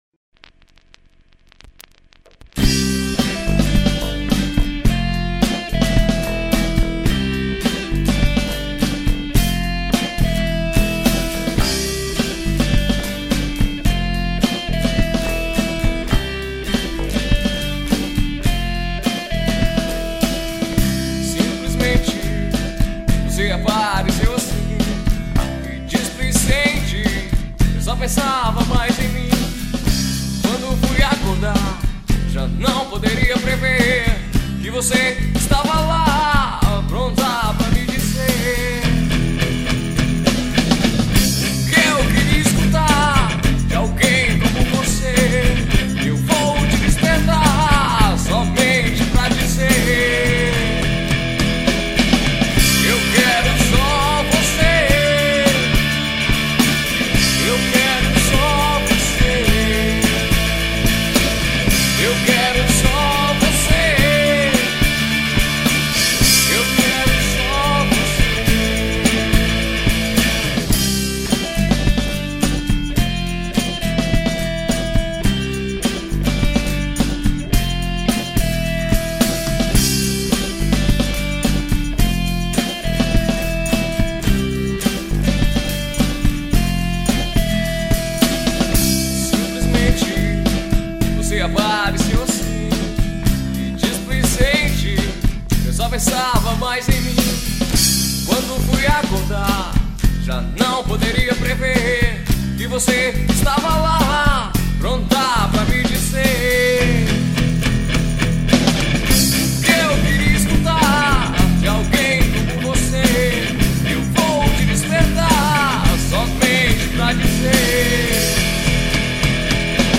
2505   03:09:00   Faixa: 3    Rock Nacional